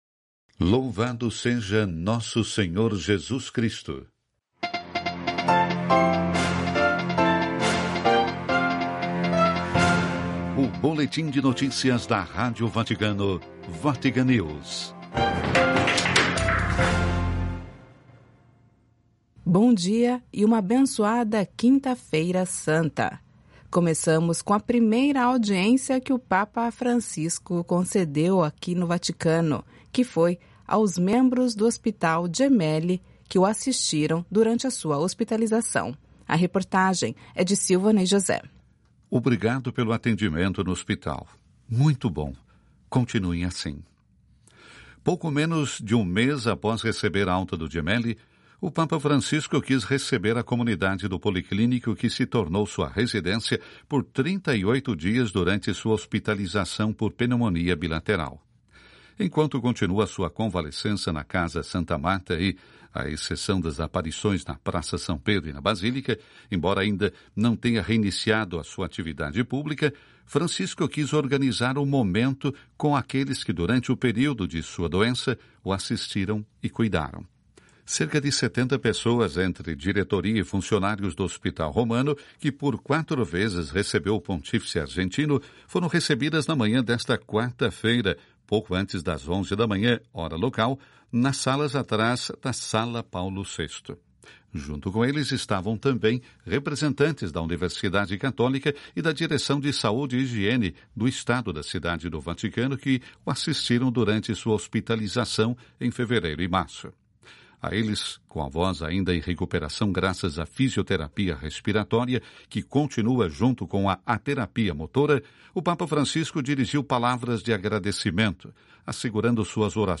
… continue reading 5 episode # vatican city # Notícias # Radio Vaticana Vatican News # 12:00